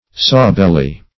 sawbelly - definition of sawbelly - synonyms, pronunciation, spelling from Free Dictionary Search Result for " sawbelly" : The Collaborative International Dictionary of English v.0.48: Sawbelly \Saw"bel`ly\, n. The alewife.